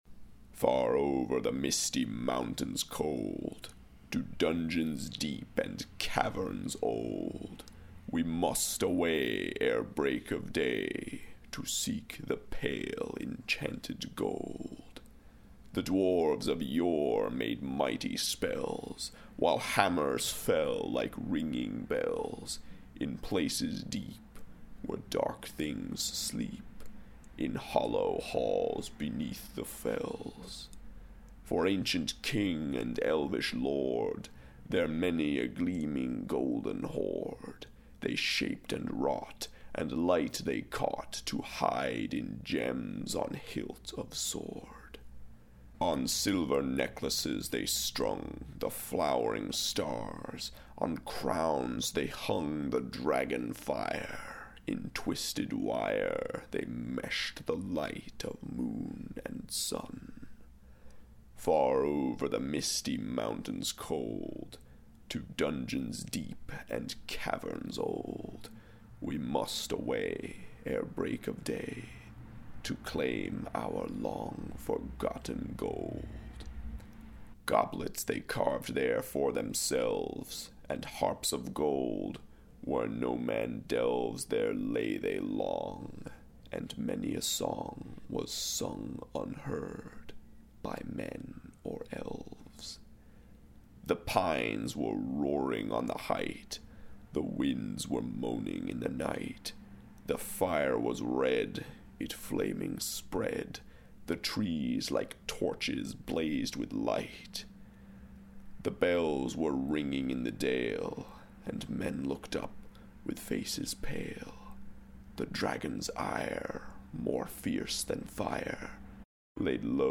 These readings are intended to educate on poetry and literature, aid in the discovery of new works, and celebrate their creators.